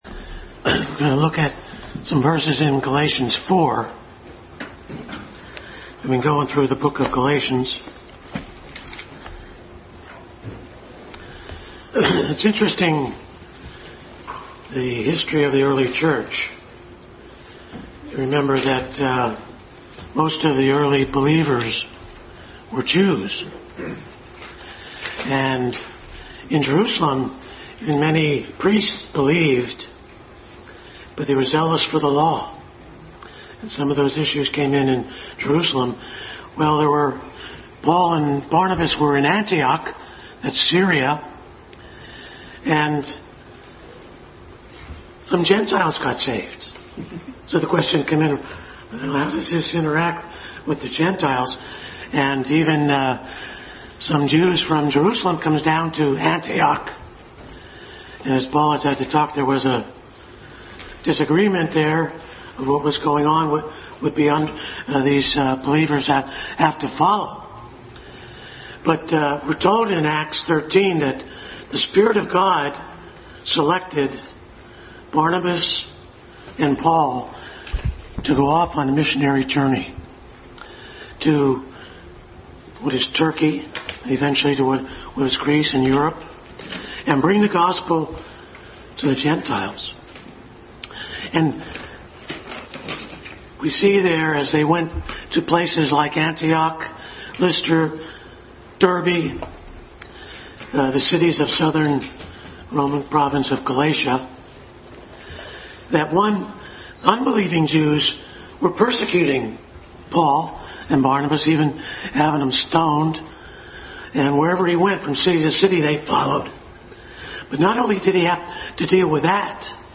Adult Sunday School Class continued study in Galatians.
Galatians 4:1-11 Service Type: Sunday School Adult Sunday School Class continued study in Galatians.